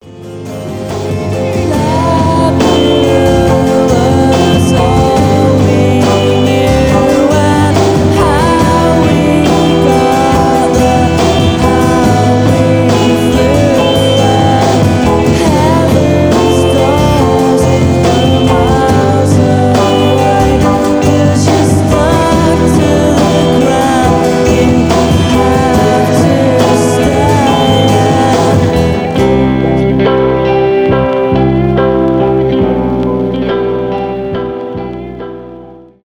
indie rock , post-britpop , атмосферные , downtempo